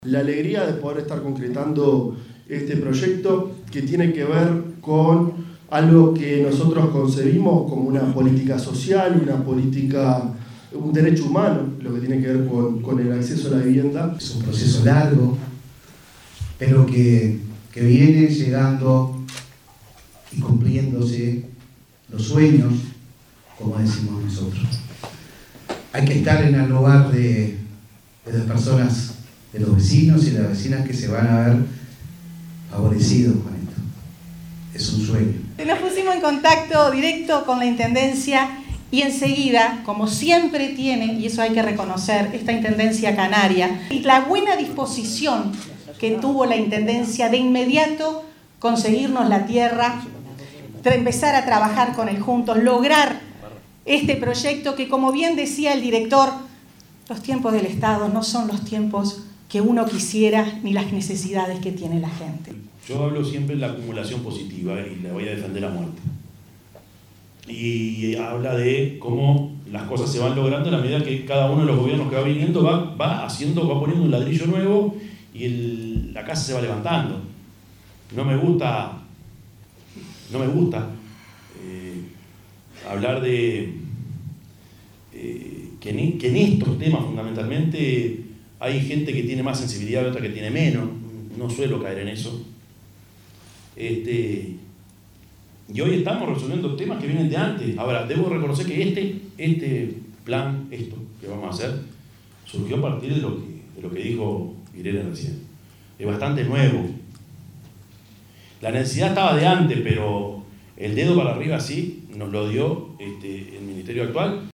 Firma de convenio entre la Intendencia y el Ministerio de Vivienda y Ordenamiento Territorial para el realojo de 40 familias de Canelones
firma_de_convenio.mp3